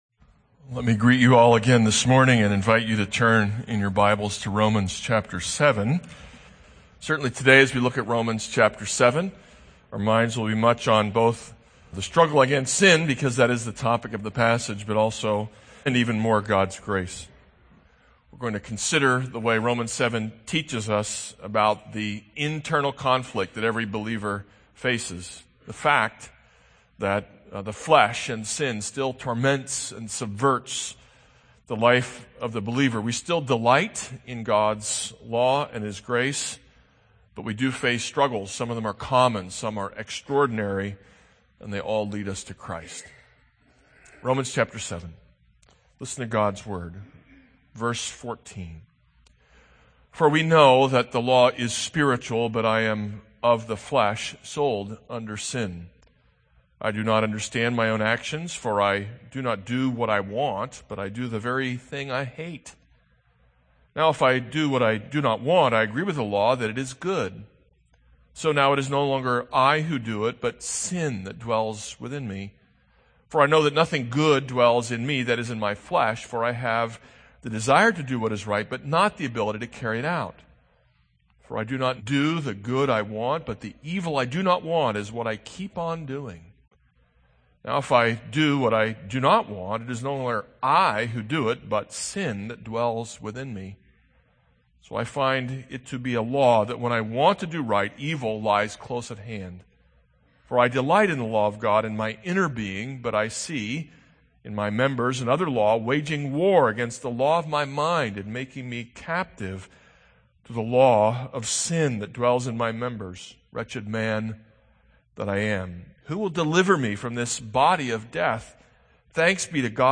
This is a sermon on Romans 7:14-25.